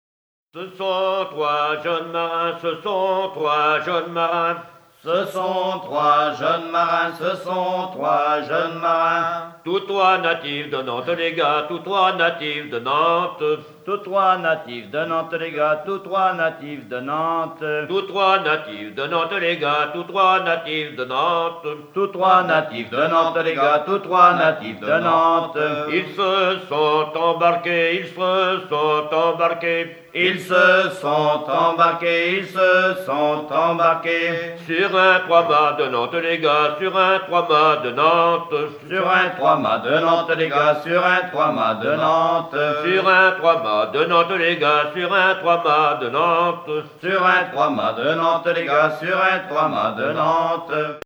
Origine : Vendée (Ile d'Yeu) Année de l'arrangement : 2010